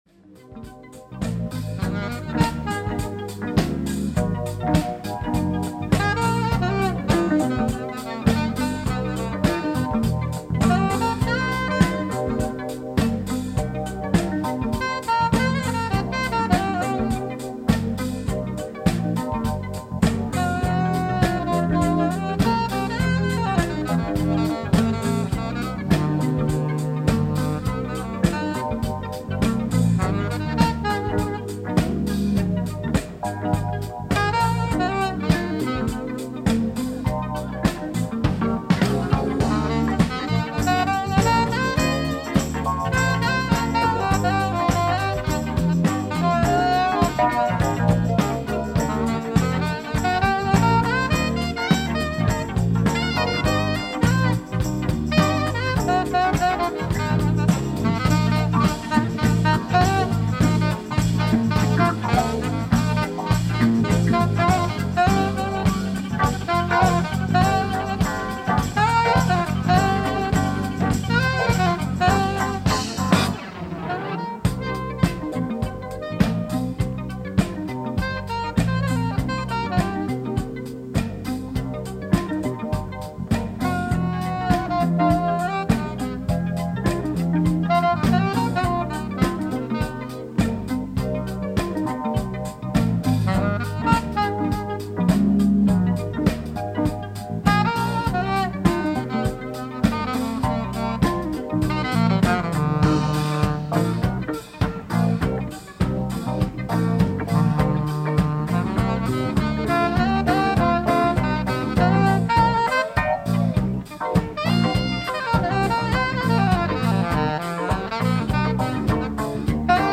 ライブ・アット・フィラデルフィア、アメリカ 06/27/1981
※試聴用に実際より音質を落としています。